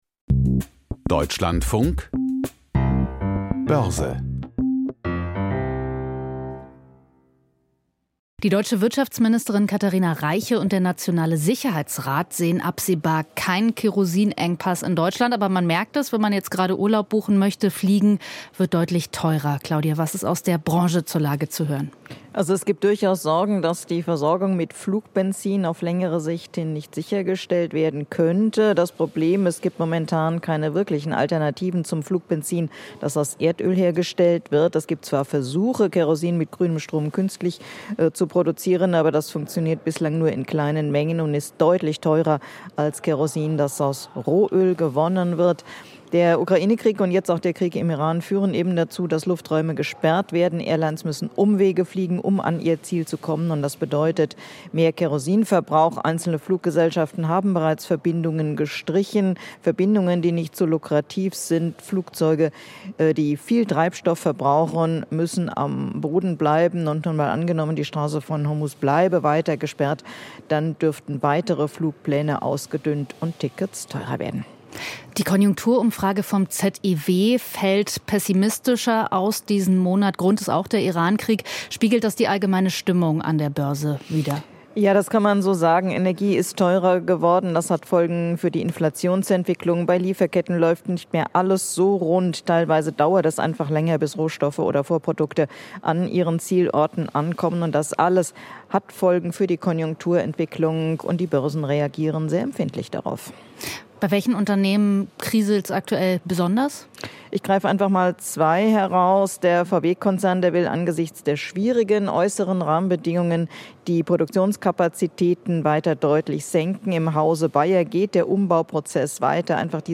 Börsenbericht aus Frankfurt a. M.